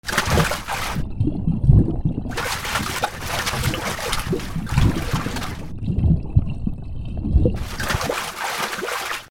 溺れてもがく 視点本人
/ M｜他分類 / L30 ｜水音-その他